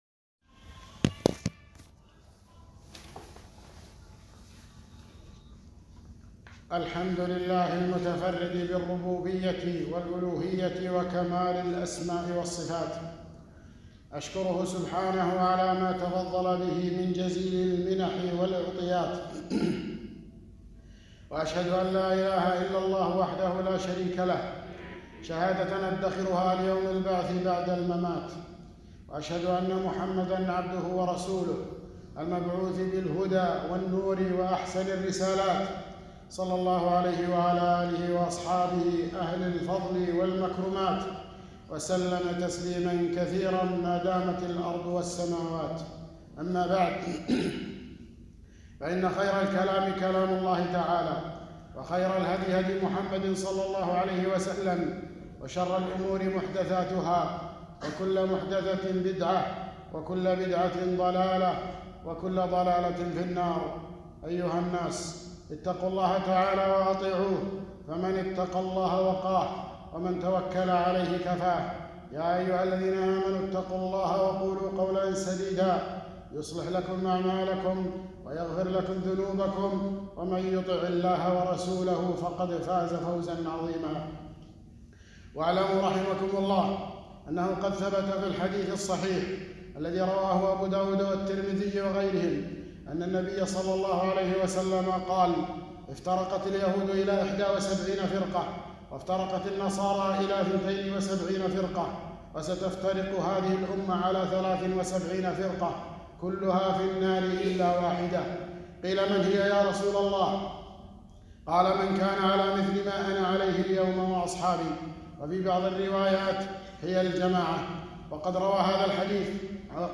خطبة - العقيدة السلفية